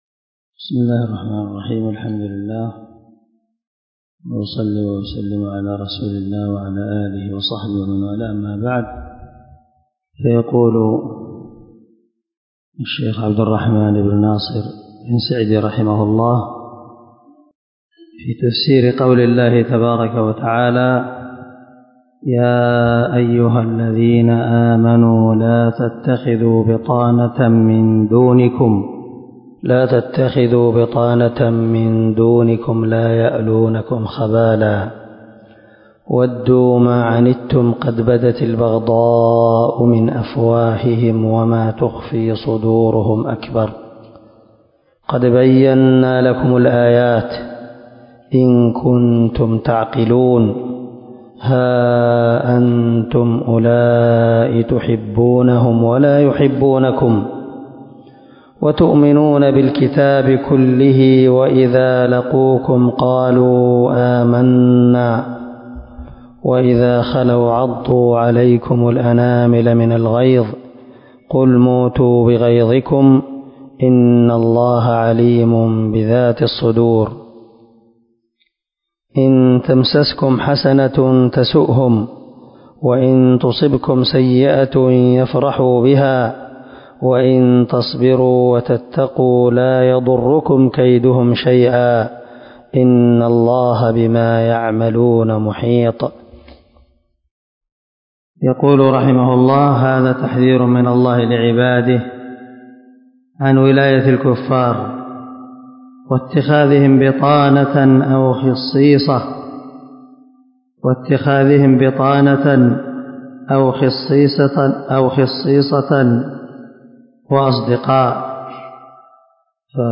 194الدرس 39 تابع تفسير آية ( 118 – 120 ) من سورة آل عمران من تفسير القران الكريم مع قراءة لتفسير السعدي